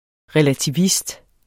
Udtale [ ʁεlatiˈvist ]